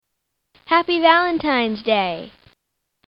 Happy Valentine's Day- Female Voice